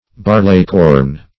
Barleycorn \Bar"ley*corn`\, n. [See Corn.]